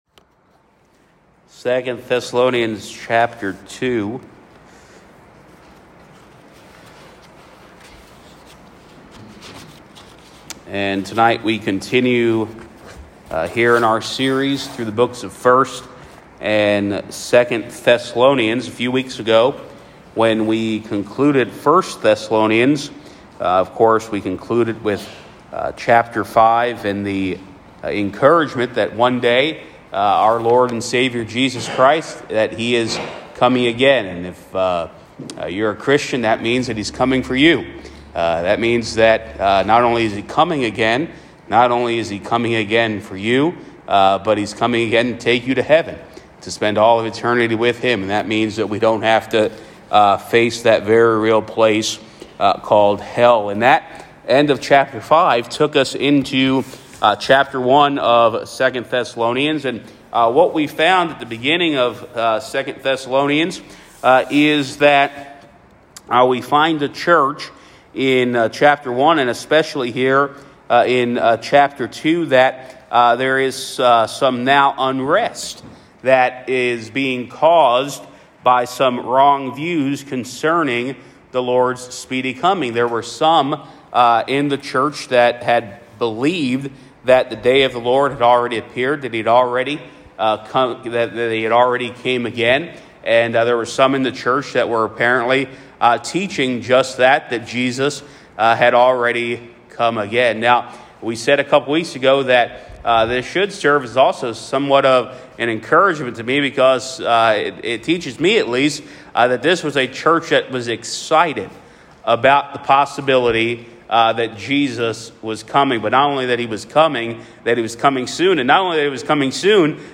Sermons | First Baptist Church of Sayre, PA